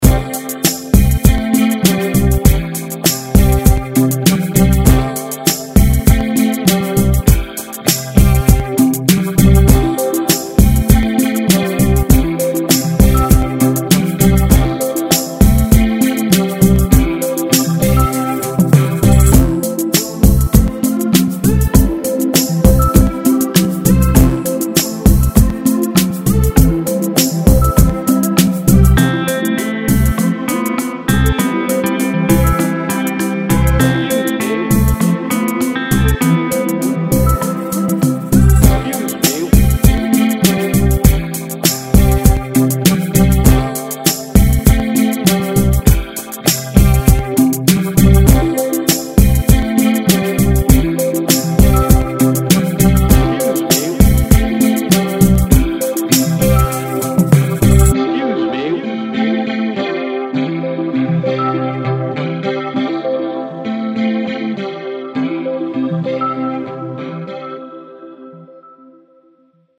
Largo [0-10] - - lounge - chill - detente - aerien - guitare
lounge - chill - detente - aerien - guitare